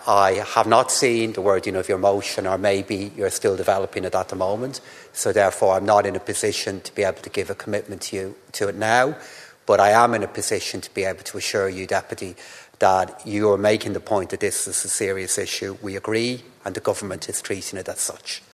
Responding, Minister Paschal Donohoe, says it’s something the government parties would agree with: